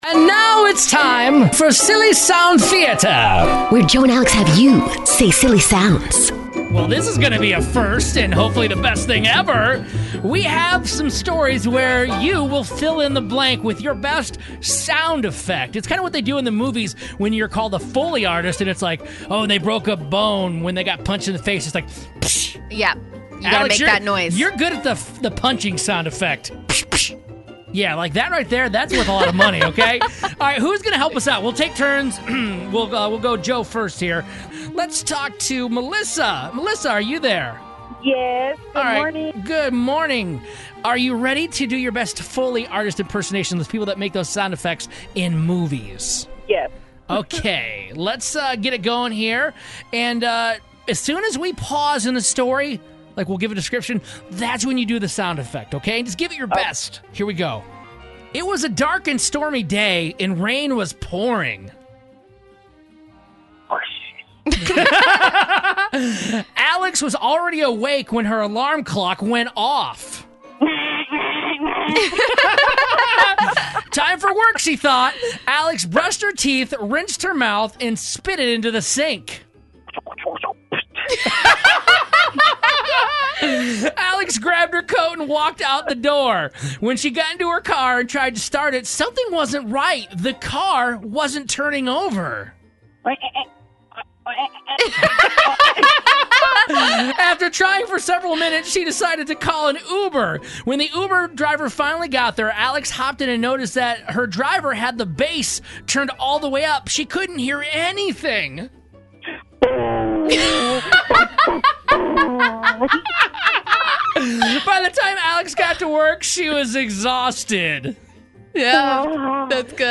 The show does their FIRST ever Silly Sound Theater where they get listeners to call in to be their sound effects for the stories